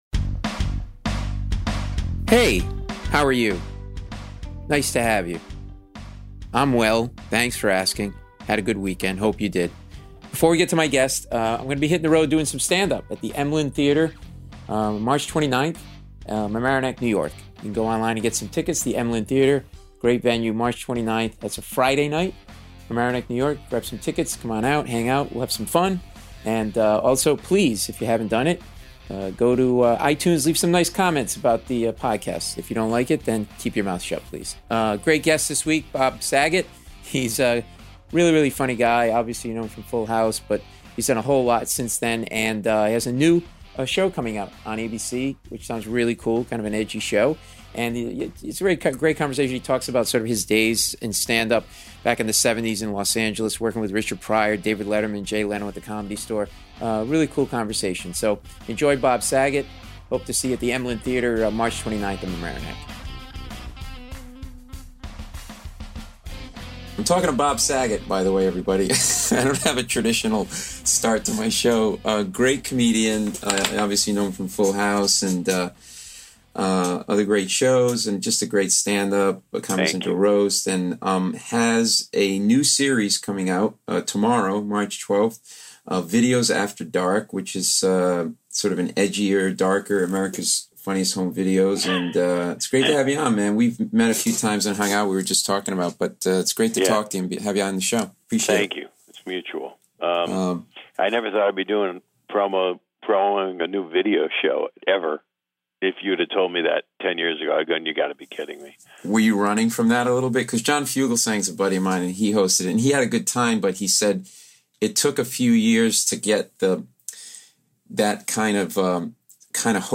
Bob Saget - Comedian/Actor (Paul Mecurio interviews Bob Saget; 12 Mar 2019) | Padverb